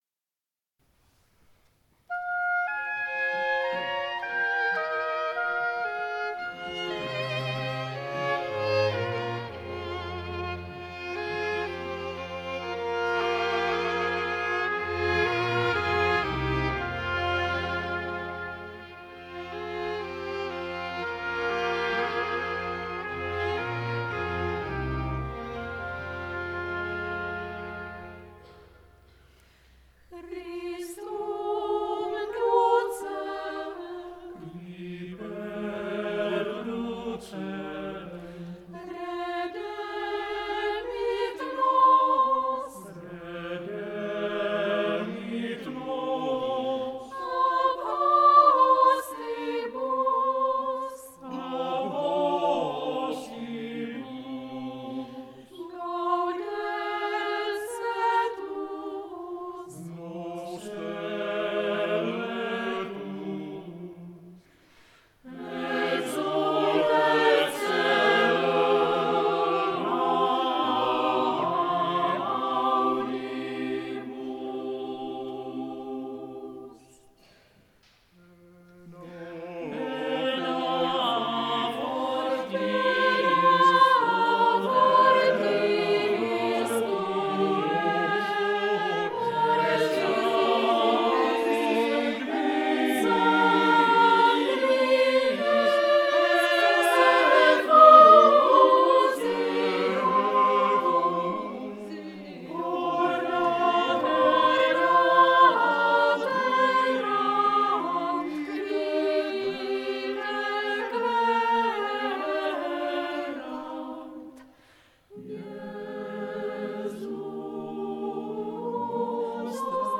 The list origins from concerts performed from 1971.